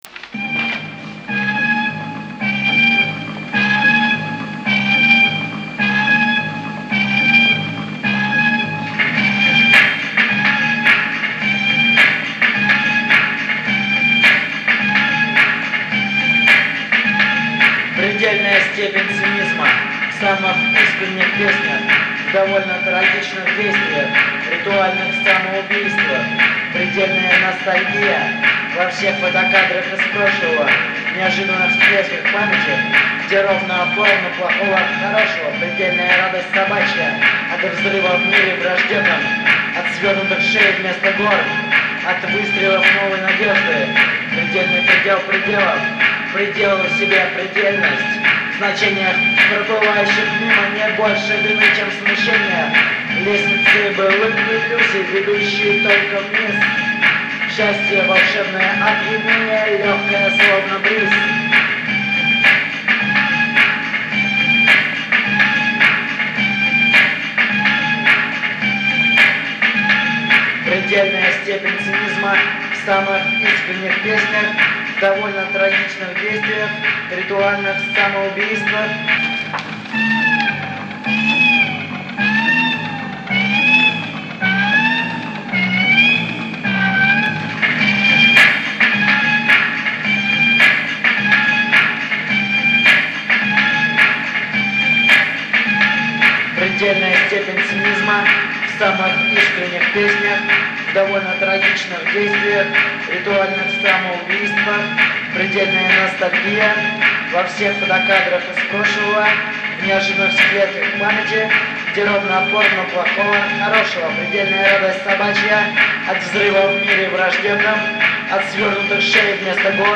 домашней студии